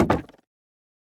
Minecraft Version Minecraft Version latest Latest Release | Latest Snapshot latest / assets / minecraft / sounds / block / bamboo_wood_fence / toggle3.ogg Compare With Compare With Latest Release | Latest Snapshot